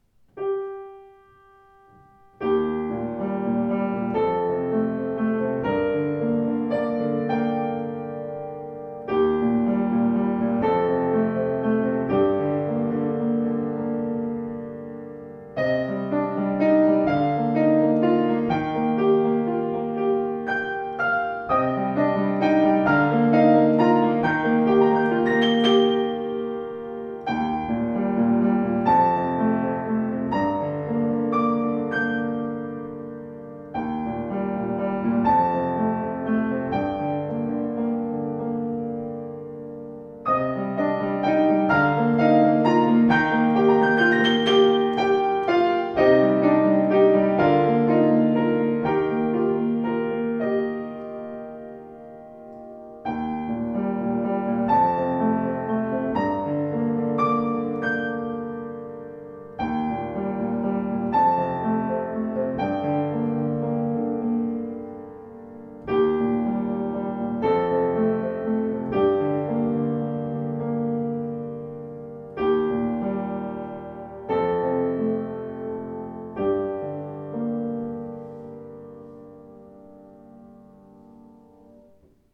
Klavier Yamaha U3 schwarz
demnächst wieder verfügbar: U3 mit vollem, konzertantem Klang in schwarz poliert